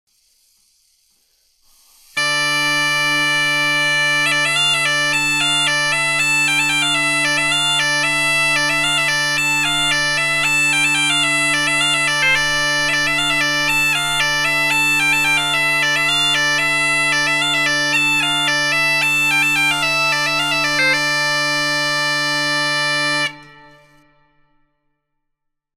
Hoch C/d Dudelsack
Klangbeispiel
Dies ist ein handlicher Dudelsack mit einem kräftigen Klang.
Tonart: C-Dur und d-moll